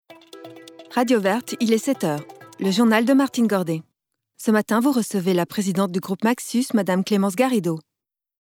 Voix off
5 - 53 ans - Mezzo-soprano